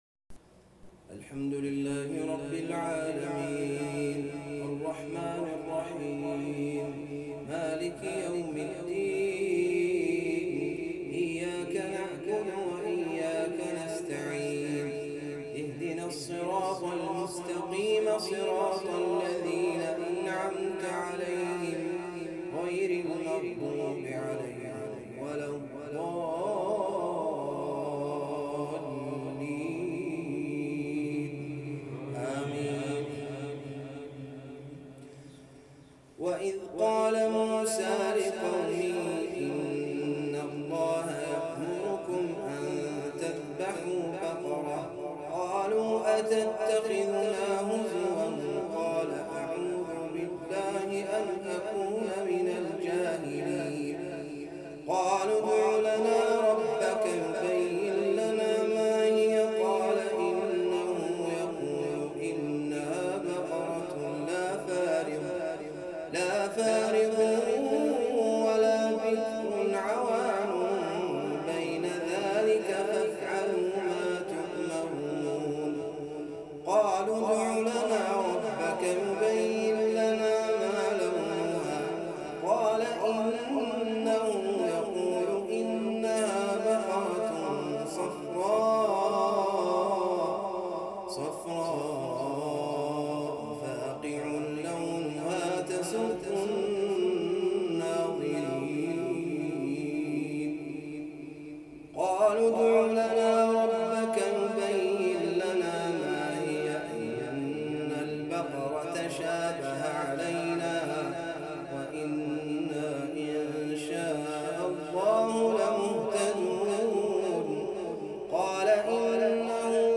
تلاوة رائعةمن تراويح الليلة ٣ رمضان ١٤٤١